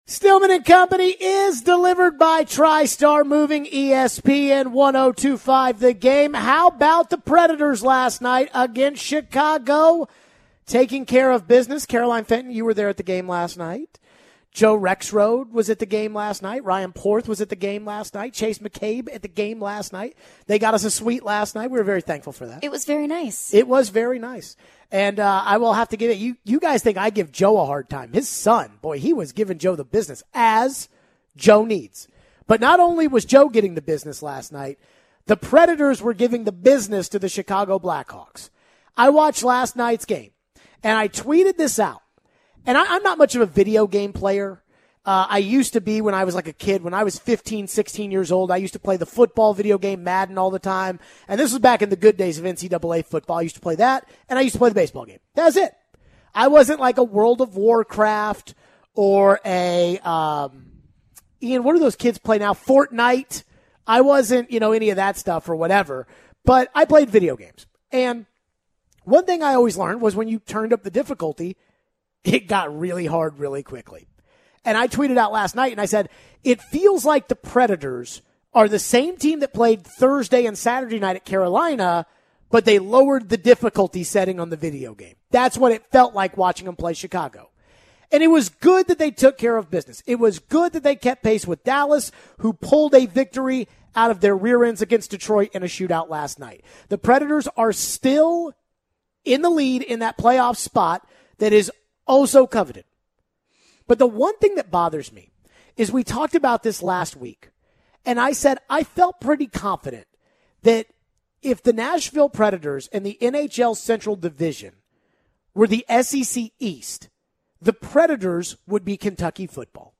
How much are they missing Tolvanen and Forsberg? We take your calls and texts.